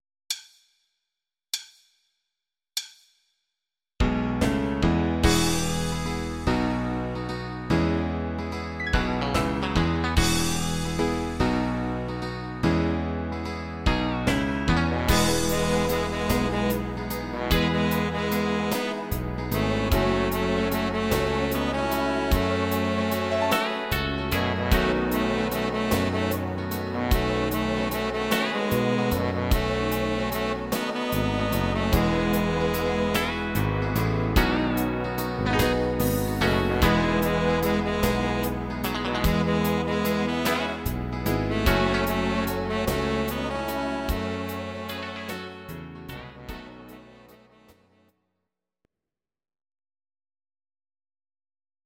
Please note: no vocals and no karaoke included.
Your-Mix: Rock (2958)